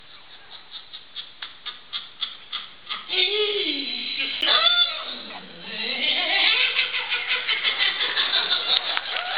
Hexadecimal Laughs
More Sounds in Laugh Soundboard
hexadecimal-laughs.mp3